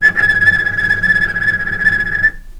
healing-soundscapes/Sound Banks/HSS_OP_Pack/Strings/cello/tremolo/vc_trm-A6-pp.aif at b3491bb4d8ce6d21e289ff40adc3c6f654cc89a0
vc_trm-A6-pp.aif